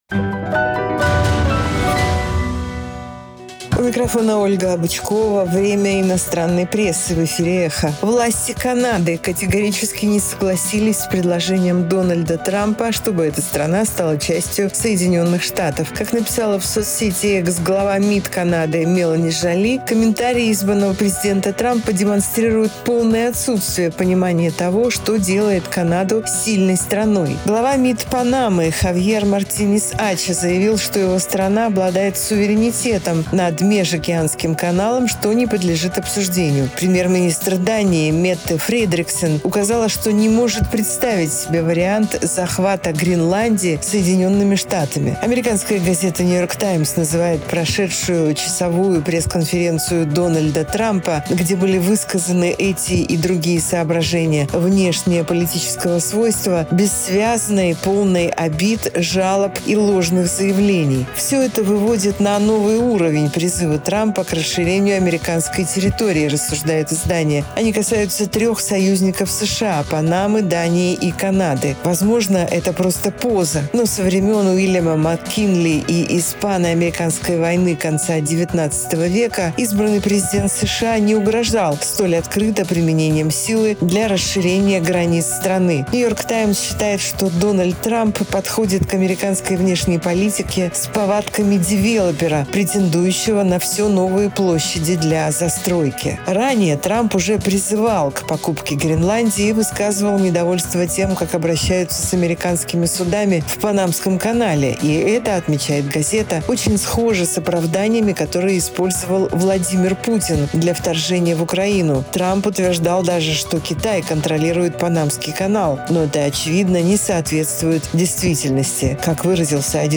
Обзор инопрессы 08.01.2025